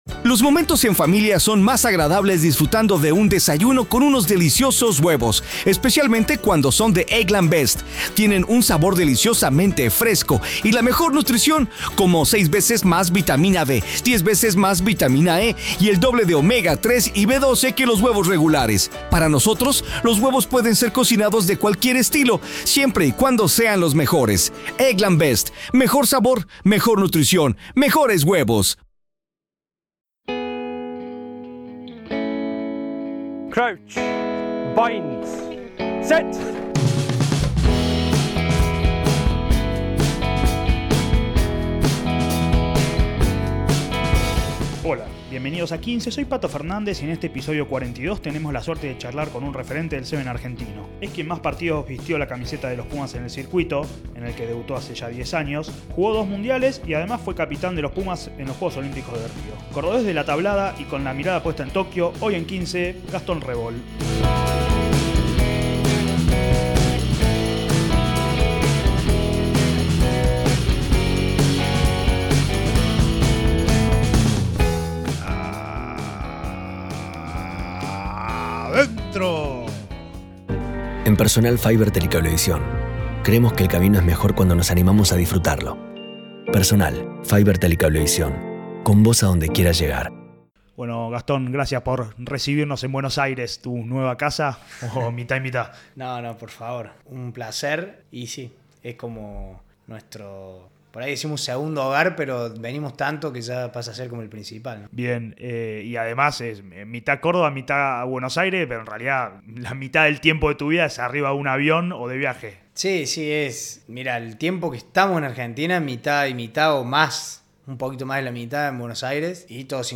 ¡Charlas de rugby con los protagonistas!